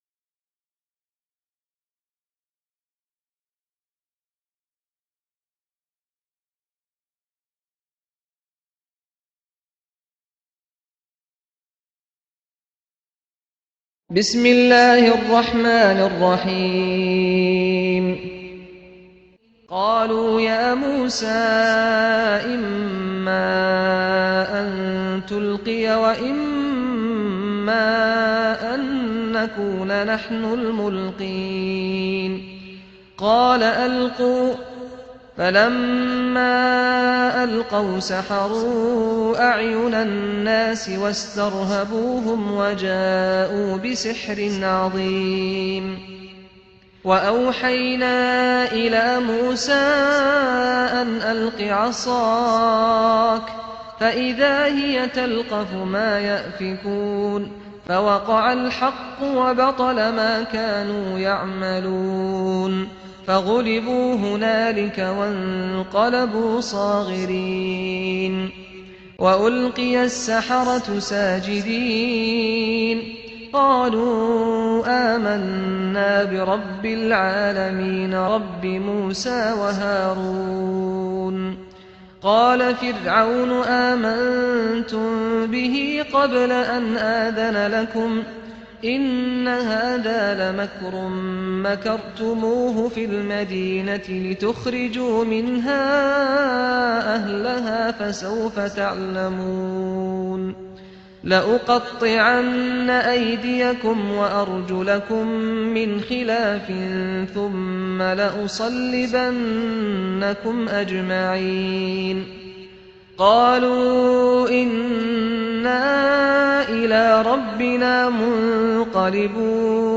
الدرس (35) تفسير سورة الأعراف - الشيخ محمد راتب النابلسي